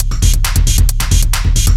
DS 135-BPM B3.wav